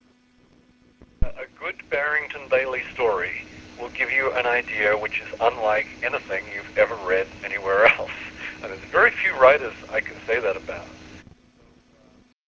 Charles Platt Interview, July 4 2000
I had the pleasure of asking him a few questions about his history with New Worlds, thoughts on the genre, publishing industry and fringe sciences as well as talking about his friend and once-collaborator, Barry Bayley. The interview was conducted on phone, from Helsinki to Arizona, on the 4th of July, 2000.